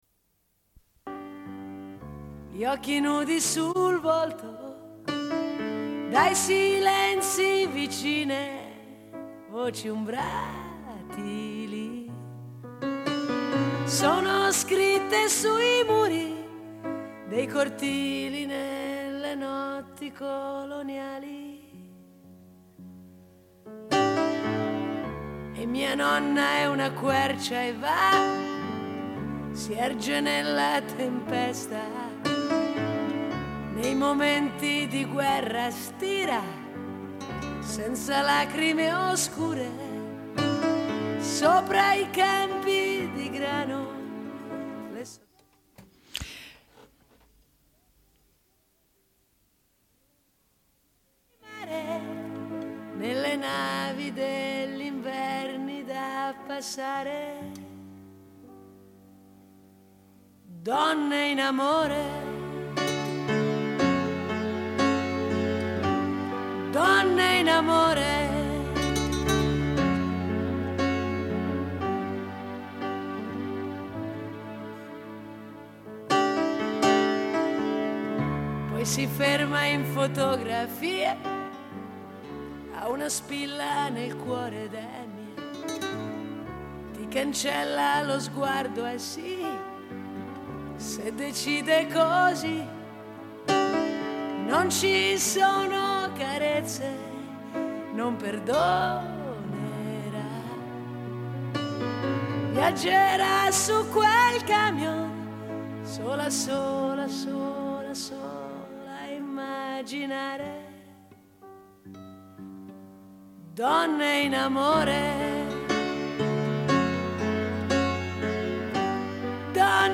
Une cassette audio, face A31:30